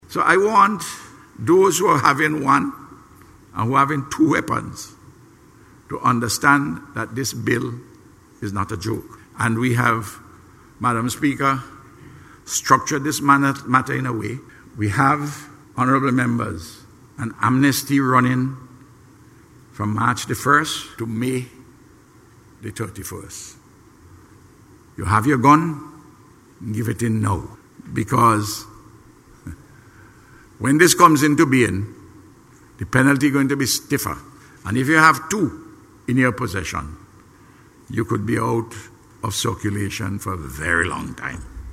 The Prime Minister made the appeal during debate on the Firearms Amendment Bill in Parliament, yesterday.